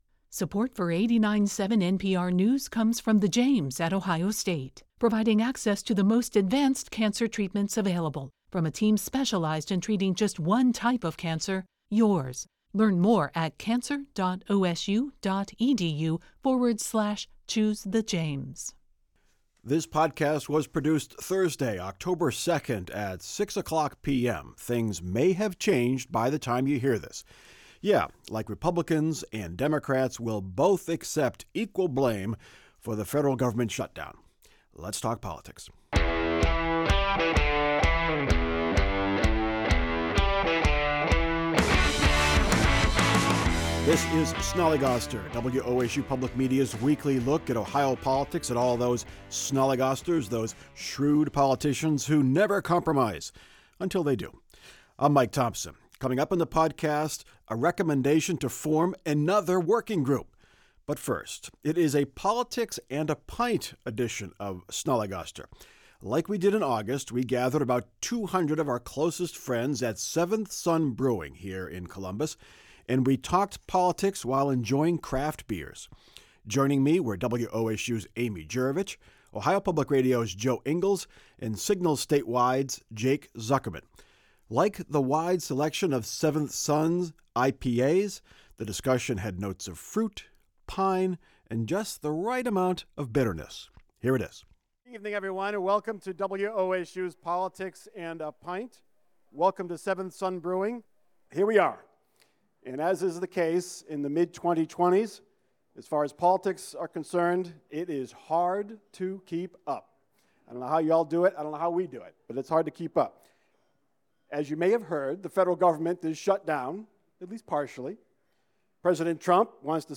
Snollygoster Politics and a Pint and a government shutdown Play episode October 3 55 mins Bookmarks View Transcript Episode Description It’s a Politics and a Pint edition of Snollygoster. As we did in August, we gathered about 200 of our closest friends at Seventh Son Brewing in Columbus, where we talked politics while enjoying craft beers.